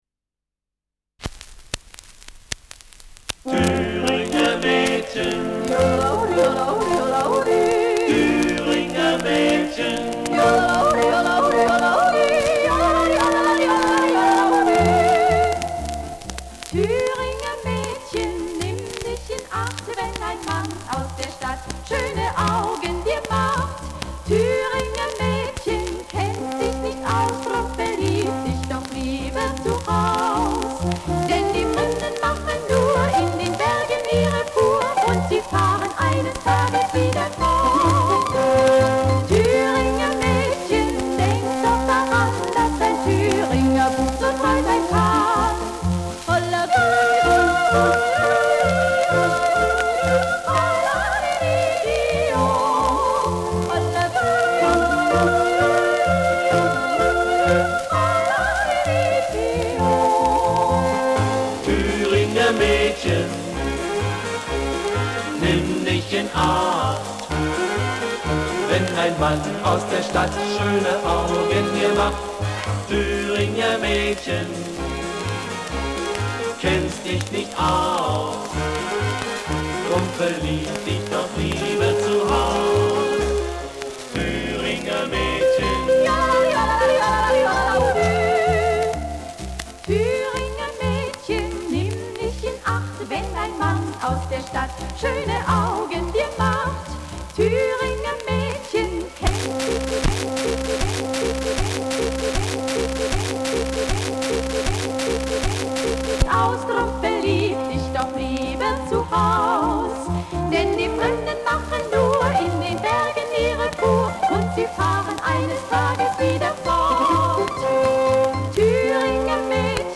Jodel-Foxtrott
Schellackplatte